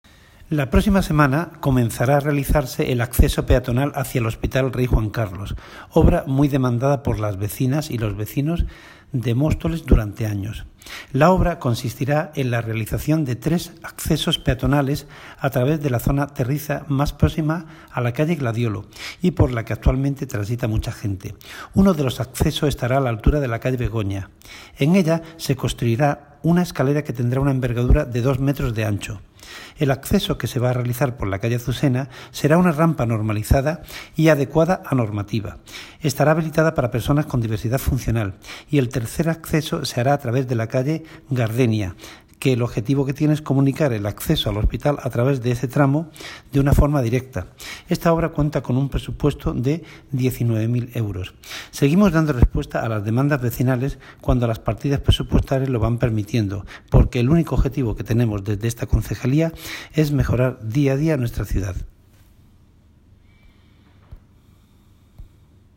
Audio - Agustín Martín, (Concejal de Obras, Infraestructuras y Mantenimiento de Vías Públicas)